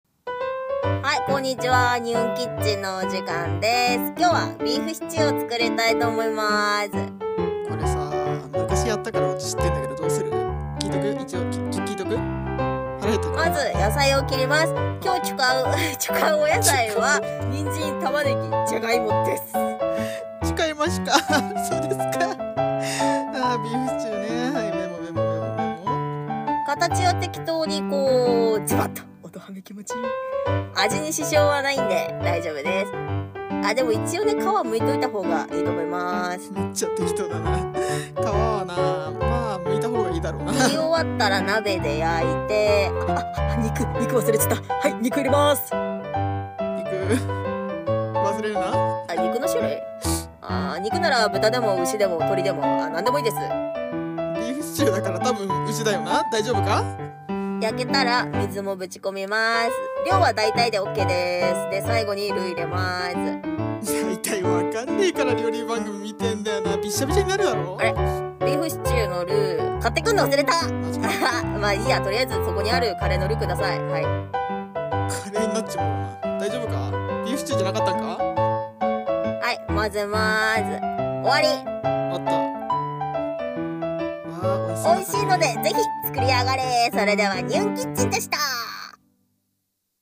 【二人声劇】適当クッキング